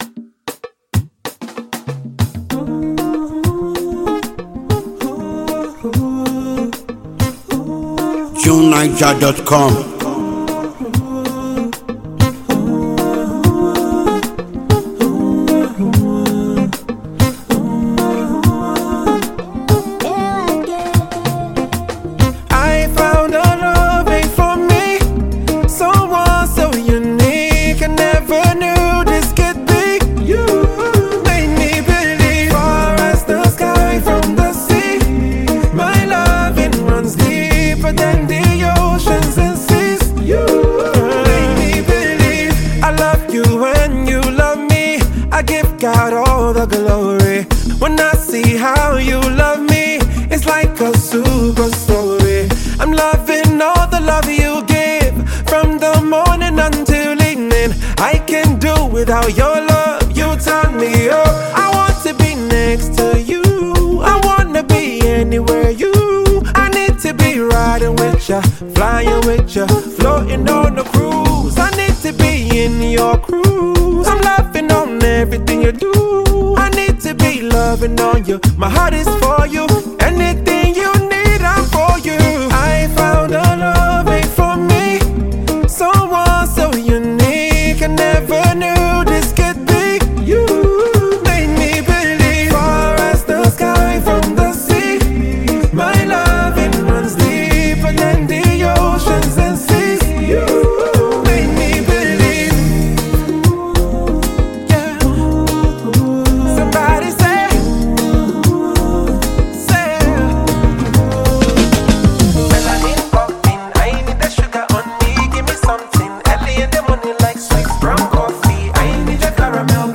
throbbing song